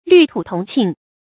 率土同慶 注音： ㄕㄨㄞˋ ㄊㄨˇ ㄊㄨㄙˊ ㄑㄧㄥˋ 讀音讀法： 意思解釋： 猶言普天同慶。